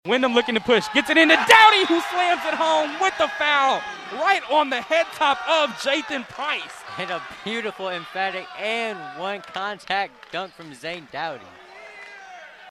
From the Decatur Central/BD Game on 1/23.